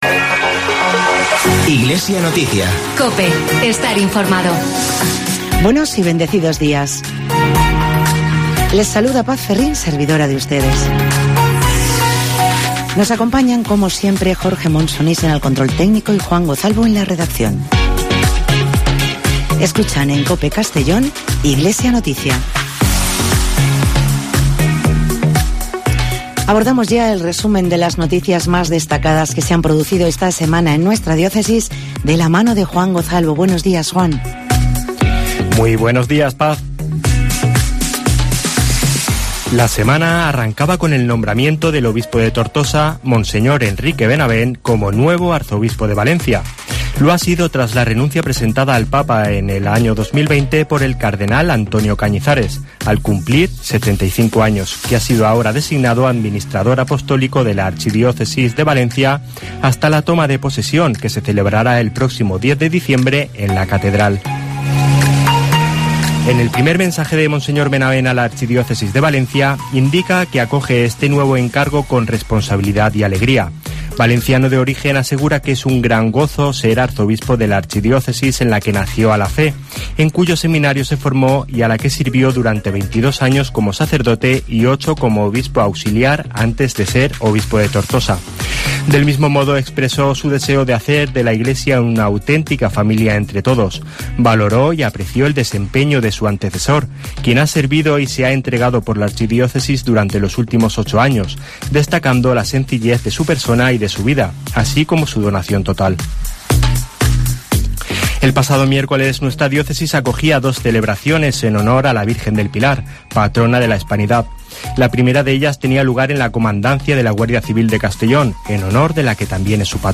Esoacio informativo de la Diócesis de Segorbe-Castellón